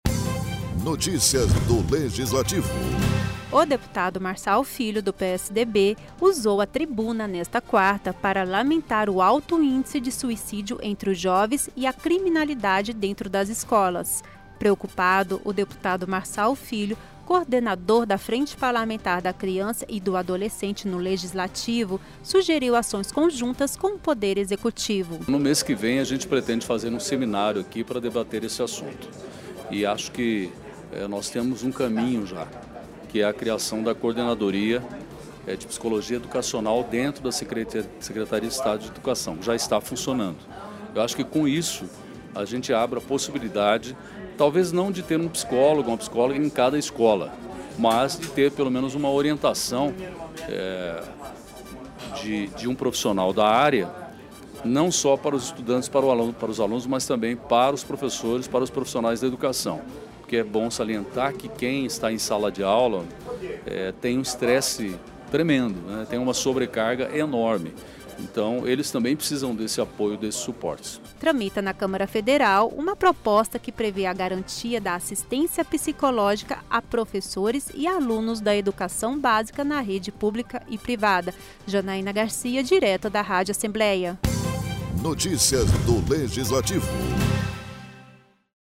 Os altos índices de suicídios entre os jovens e a violência dentro das escolas foram temas debatidos pelo deputado Marçal Filho (PSDB) durante a sessão desta quarta-feira (27), na Assembleia Legislativa. O parlamentar destacou as ações dos psicólogos no ambiente escolar.